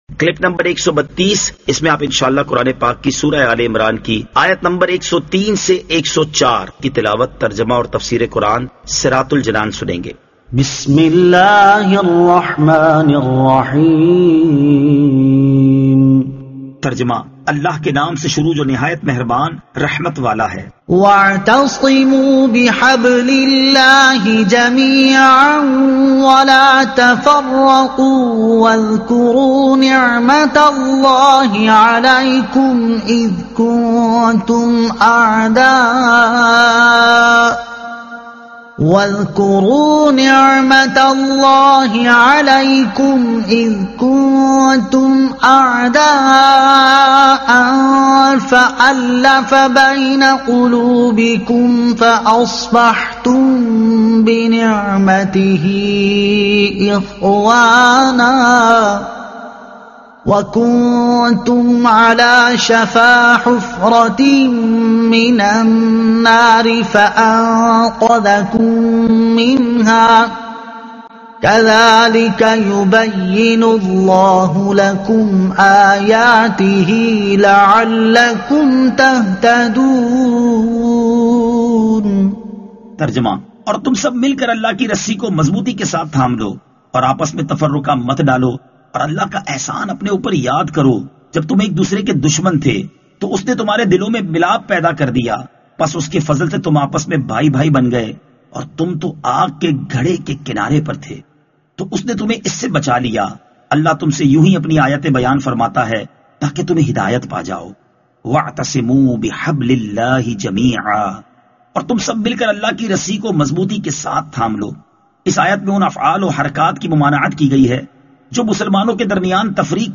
Surah Aal-e-Imran Ayat 103 To 104 Tilawat , Tarjuma , Tafseer